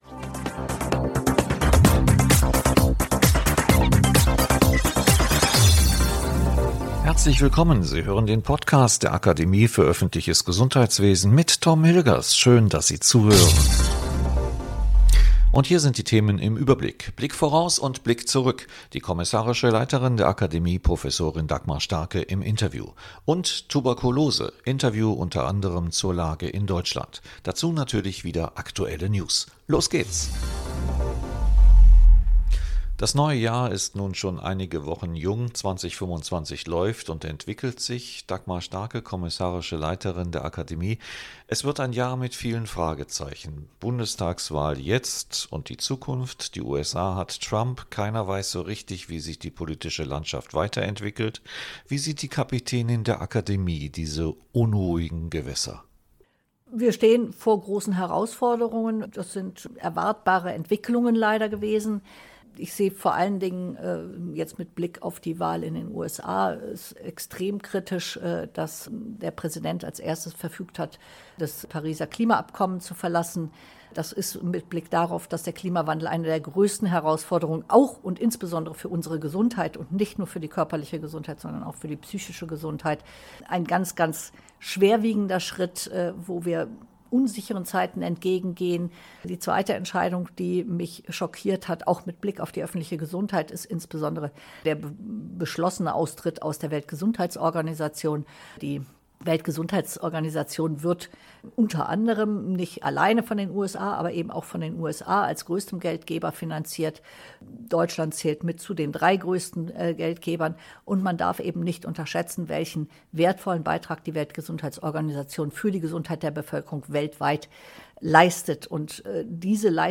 Außerdem im Interview